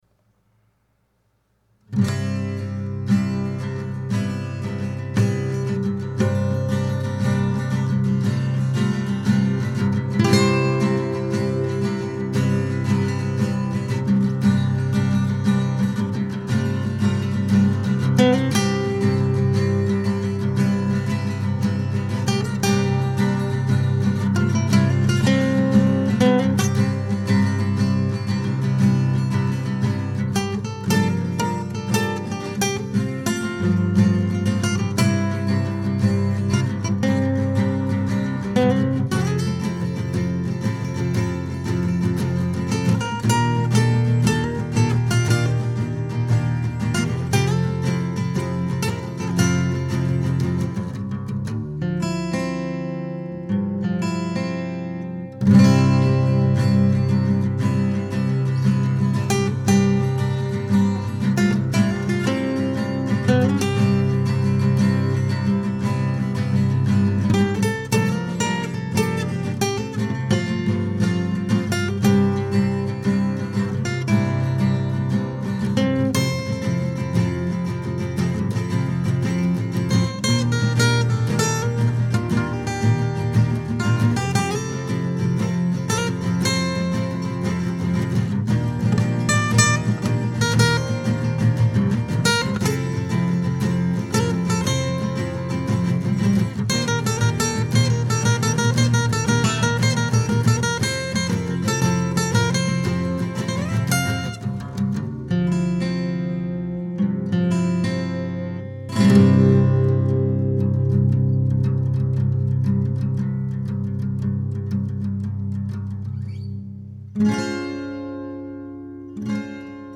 However, to break up the monotony, Track 12 was an acoustic instrumental called “Fjorc”… a tune I liked so much that I decided to record a second time outside of its original album context.
Production, mixing, guitars by me. Guitar freaks: Drop-C tuning (C G C F A D), rhythm part is double tracked